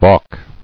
[balk]